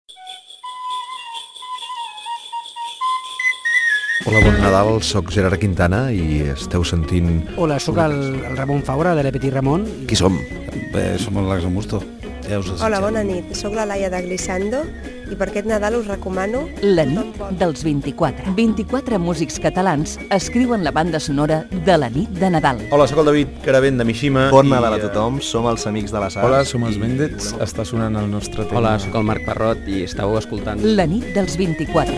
Promoció del programa amb algunes de les veus dels músics que hi intervindran